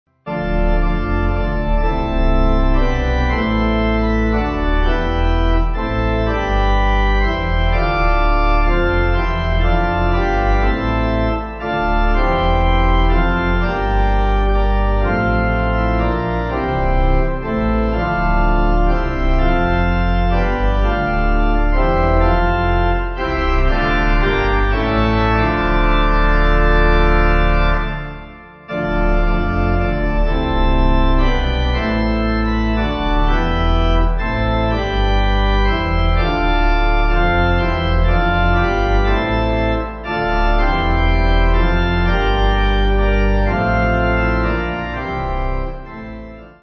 Organ
(CM)   6/Dm